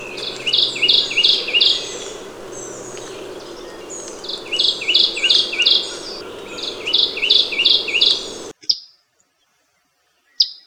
Kentucky Warbler
Reinita de Kentucky
Oporornis formosus